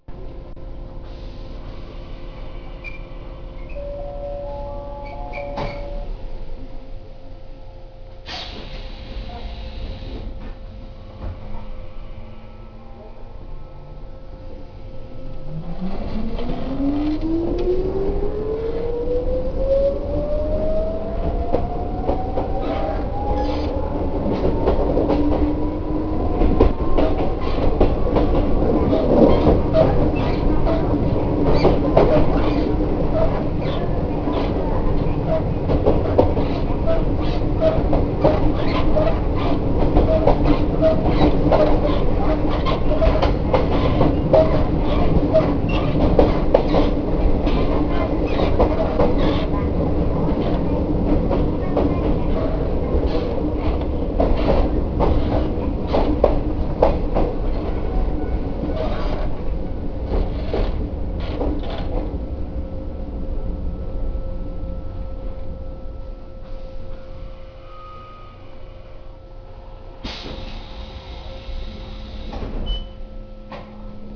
・1000形走行音
【水間線】三ケ山口→三ツ松（1分14秒：404KB）
不思議な見た目に改造されているものの、走行装置は抵抗制御のまま弄られていません。ドアチャイムと発車メロディが搭載されているのが面白い特徴。ドアチャイムはJR東海と同じです。発車メロディは車内スピーカーからしか流れないので、意味があるのかはよくわかりません。